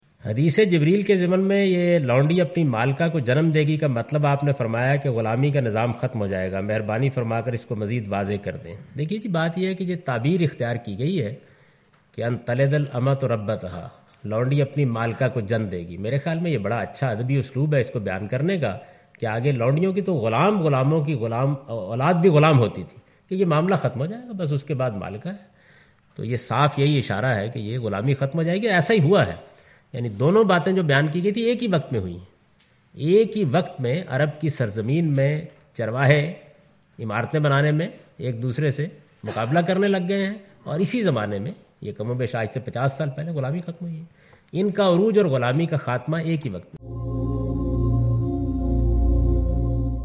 Question and Answers with Javed Ahmad Ghamidi in urdu
جاوید احمد غامدی کے ساتھ اردو میں سوال و جواب